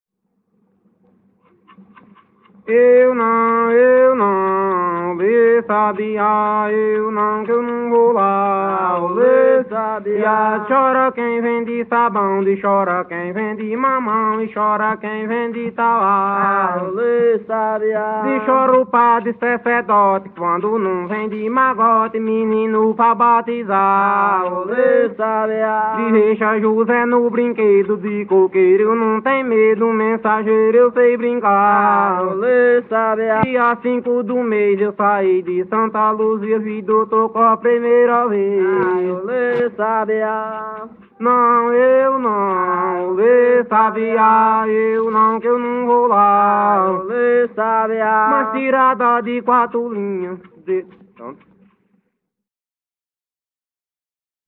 Coco embolada/galope – “”Adeus sabiá”” - Acervos - Centro Cultural São Paulo